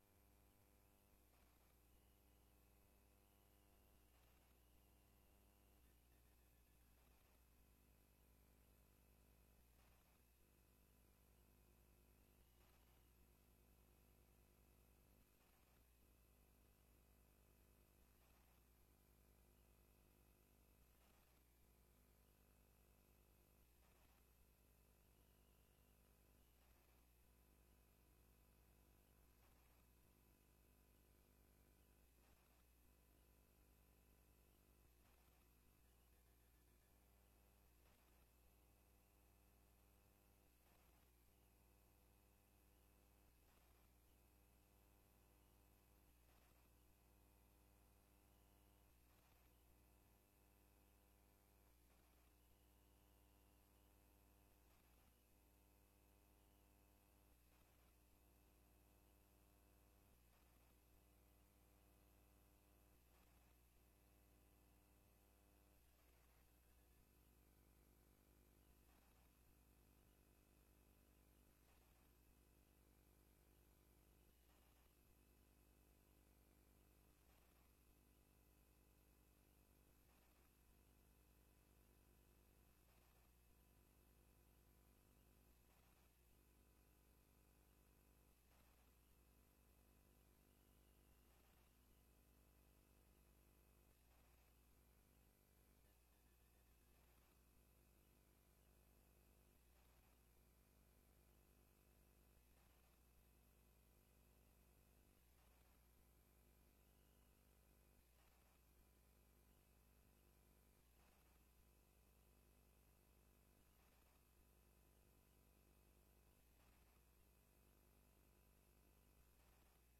Algemene Raadscommissie in De Beeck, Molenweidtje 2, 1862 BC Bergen.